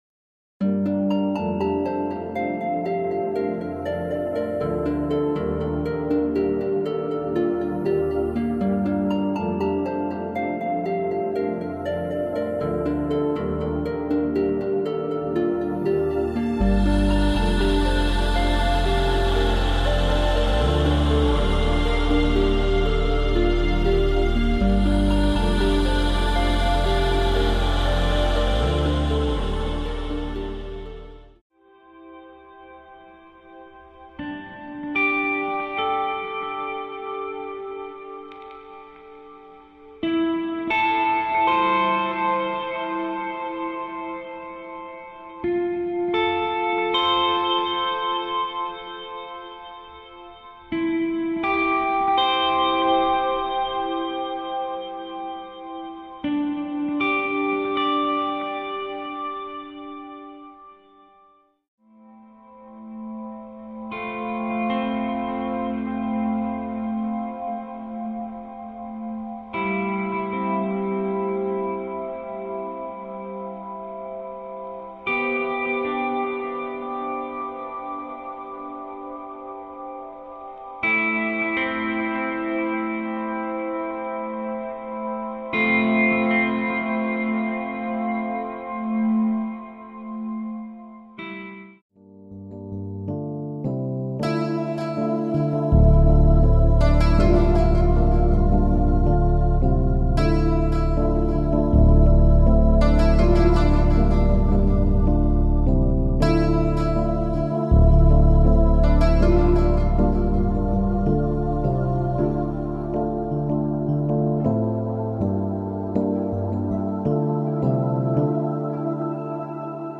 styl - chillout/relaxation